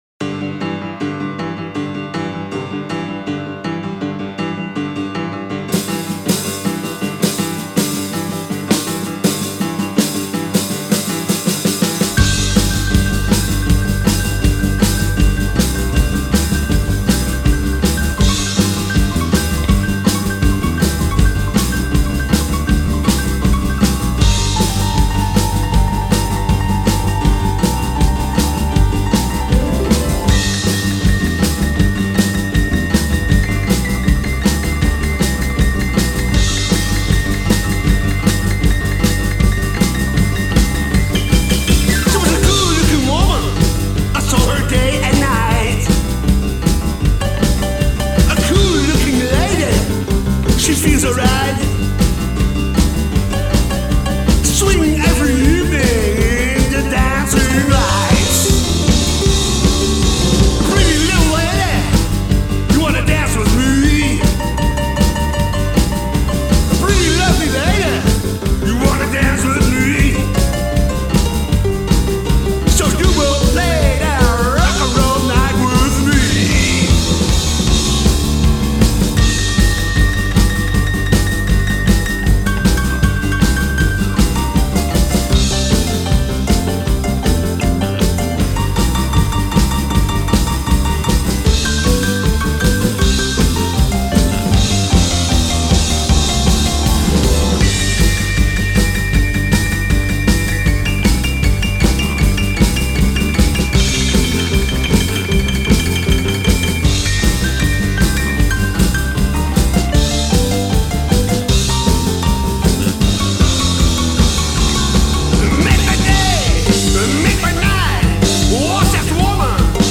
drums). Zij verzorgen tevens de backing vocals.
pianorock